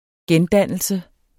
Udtale [ ˈgεnˌdanˀəlsə ]